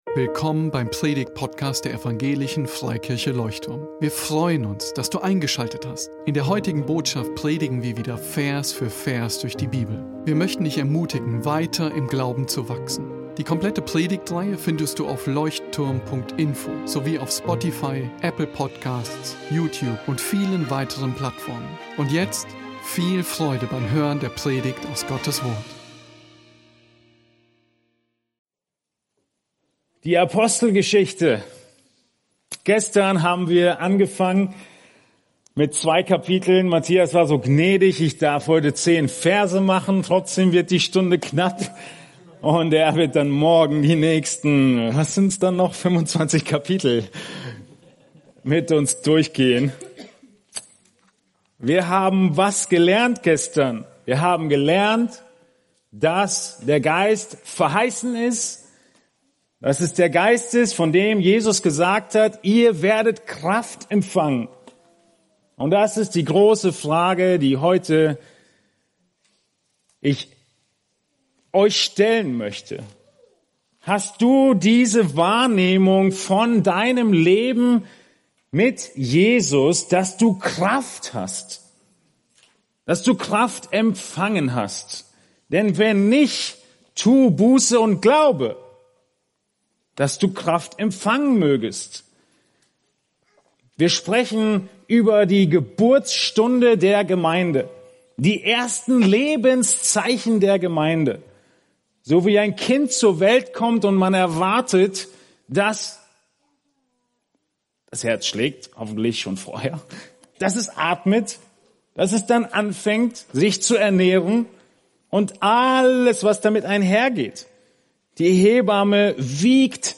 Gemeindefreizeit 2022 | Vortrag 2